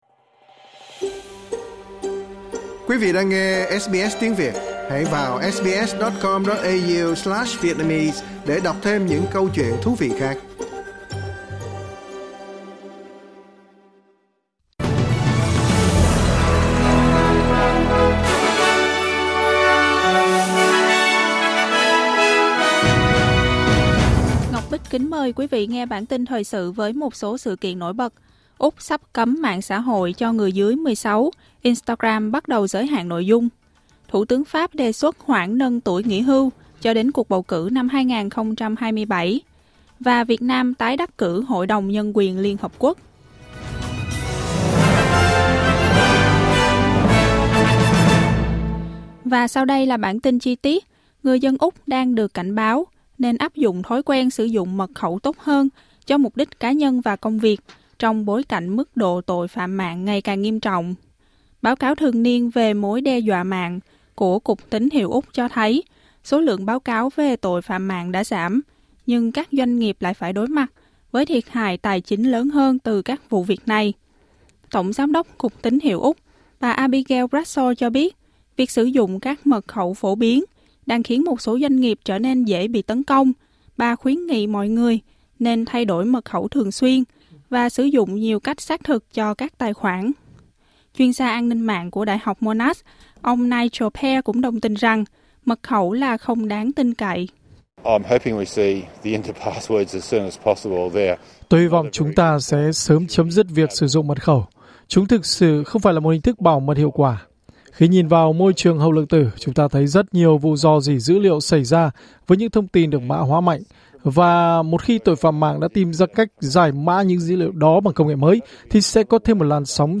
Một số tin tức trong bản tin thời sự của SBS Tiếng Việt.